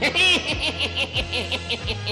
Funny Laugh Sound Effect Sound Button | Sound Effect Pro
Instant meme sound effect perfect for videos, streams, and sharing with friends.